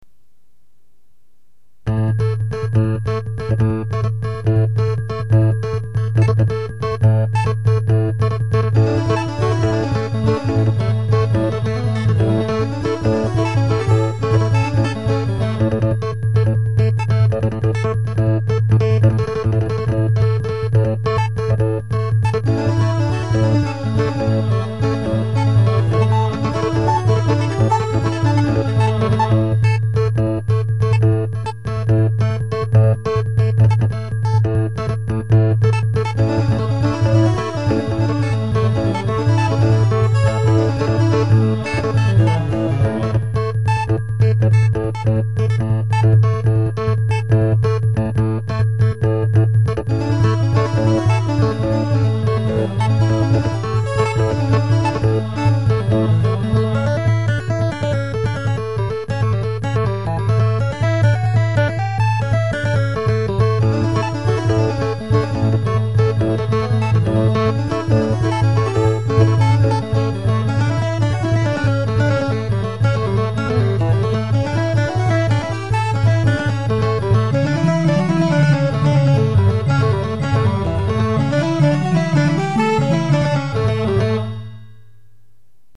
1,342 KB 1:25 Phony Indian Raga